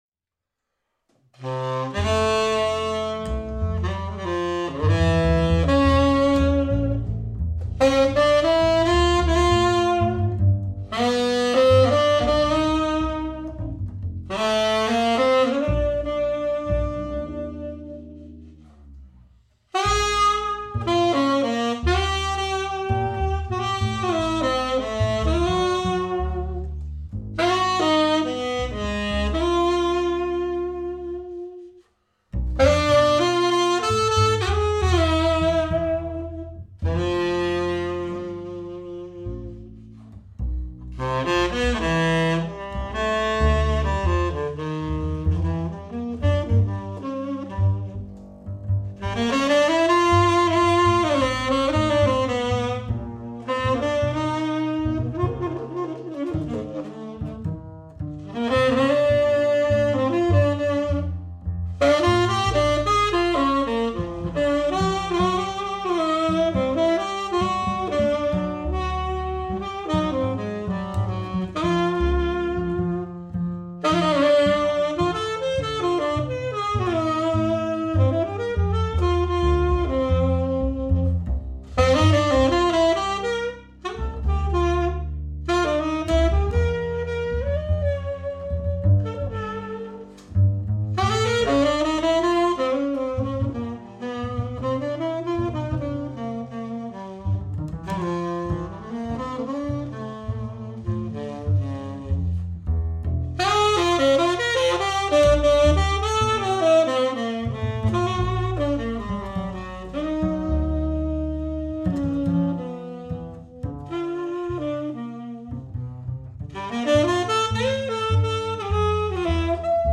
double bass
tenor sax and ney